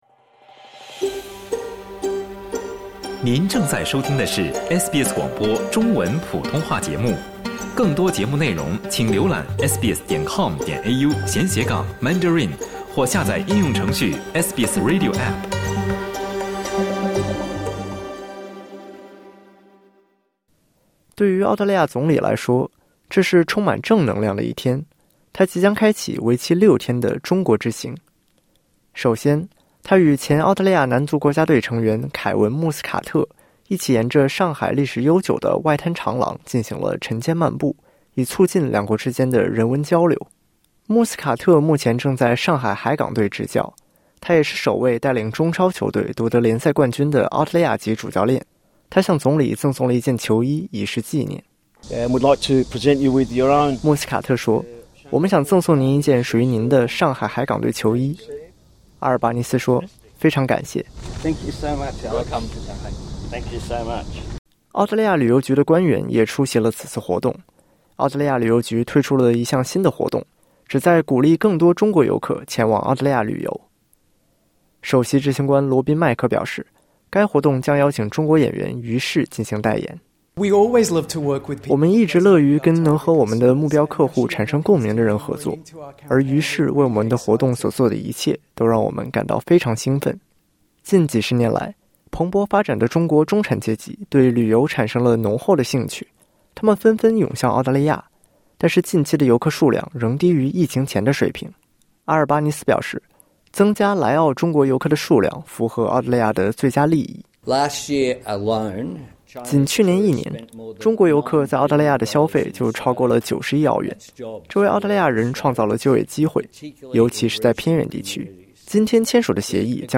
在访问中国的首个完整日程中，澳大利亚总理不得不努力回避一个显而易见却又棘手的问题——那就是与美国的军事联盟关系（点击音频，收听完整报道）。